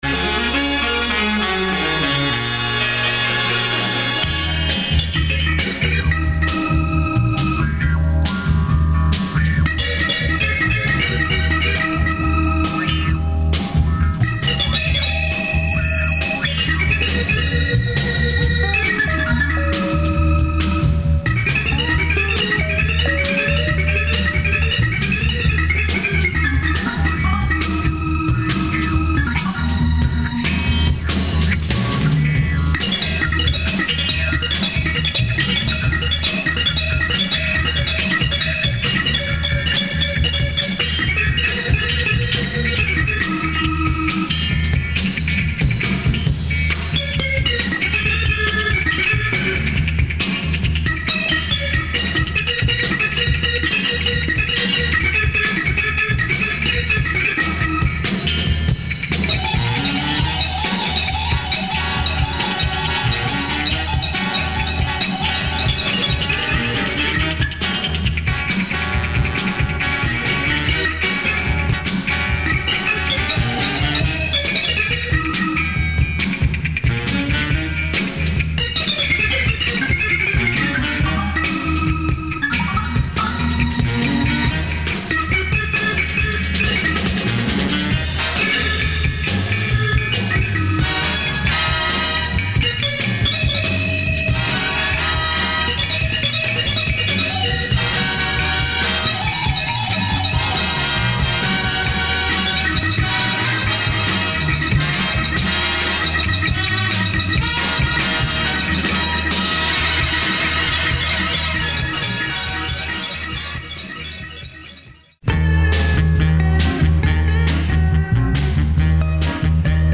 an obscure Japanese jazz band
Terrific organ groove pop here !